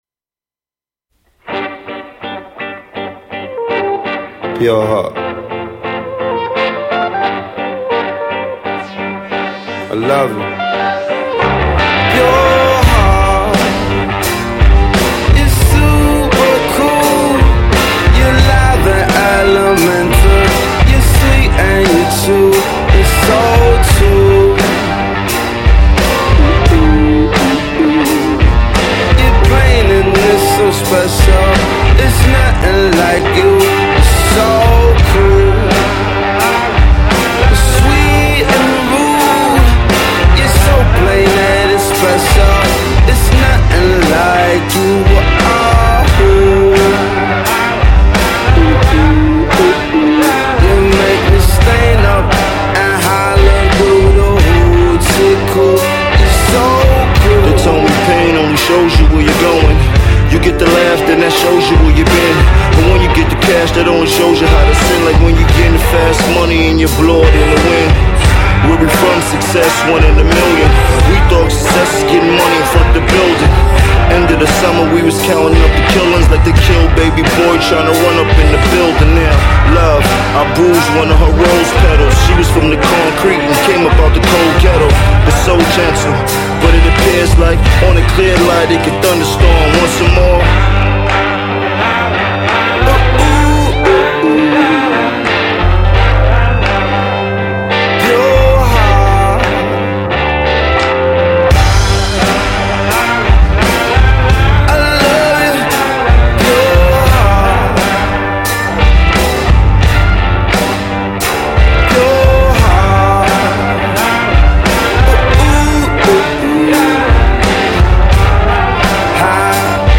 laying down the blues rock underbelly
a whole slew of hip hop titans